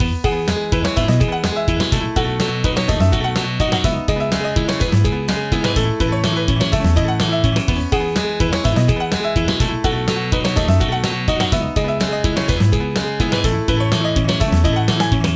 音楽ジャンル： ロック
LOOP推奨： LOOP推奨
楽曲の曲調： MIDIUM